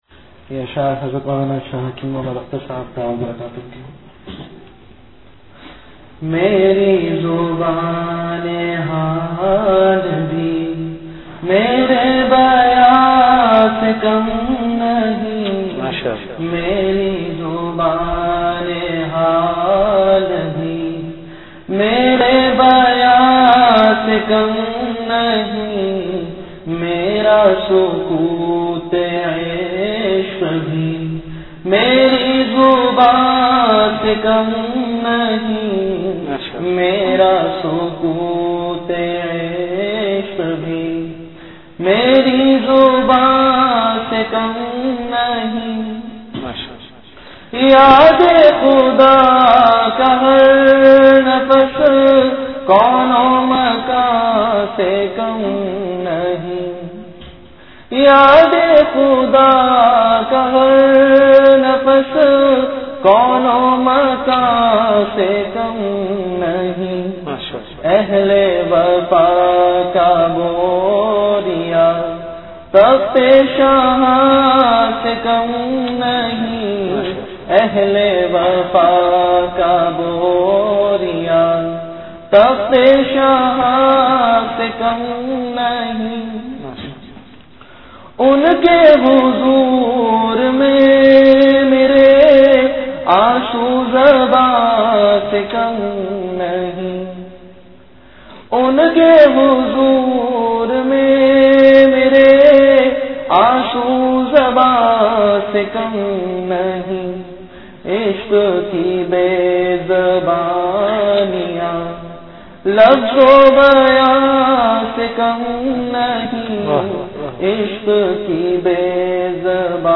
Majlis-e-Zikr
Event / Time After Isha Prayer